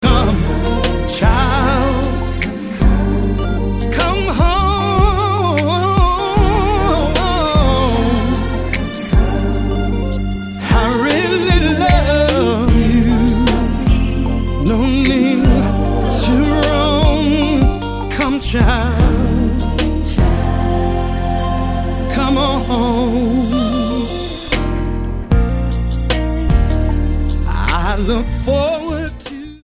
STYLE: Gospel
is a nicely soulful ballad